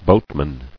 [boat·man]